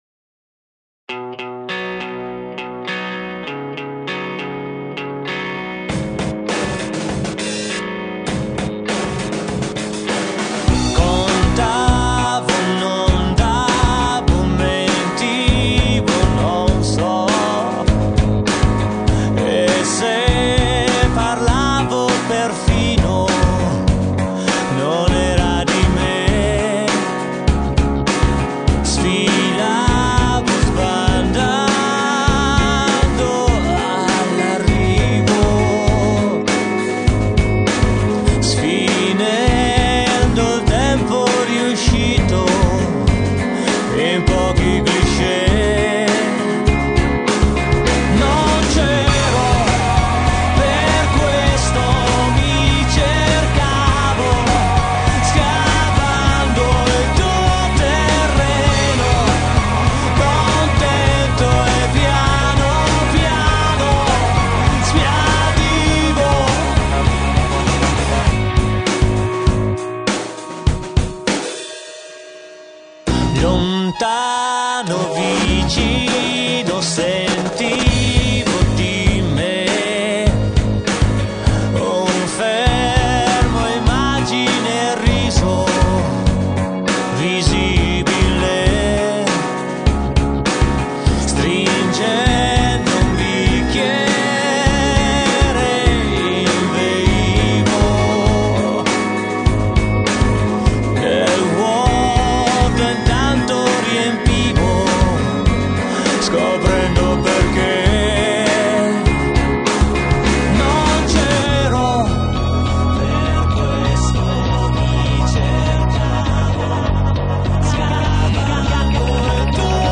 MUSIC>alternative rock
Chitarra
Basso
Voce
Batteria